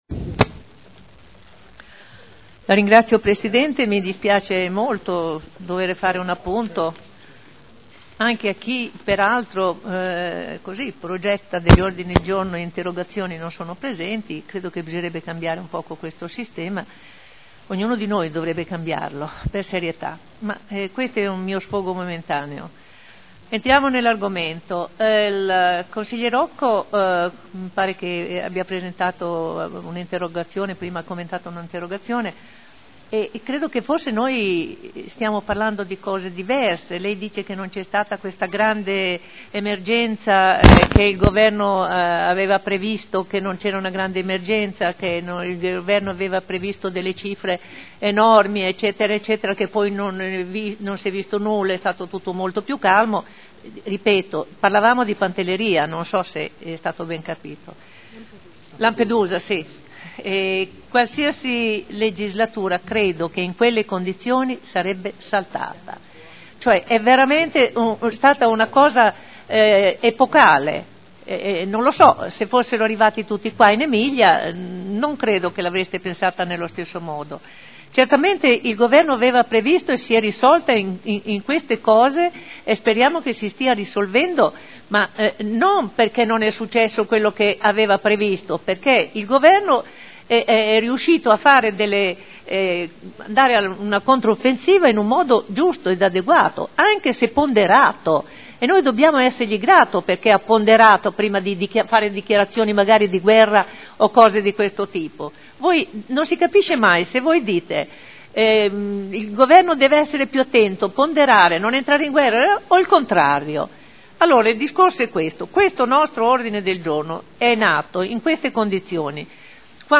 Seduta del 28/04/2011. Dibattito su mozione presentata dal Pd e su odg del Pdl sull'emergenza immigrazione.